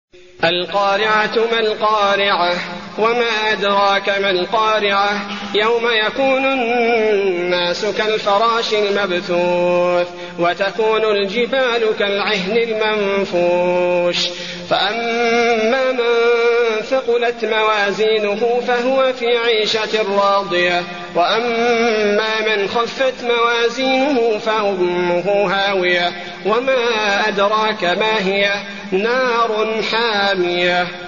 المكان: المسجد النبوي القارعة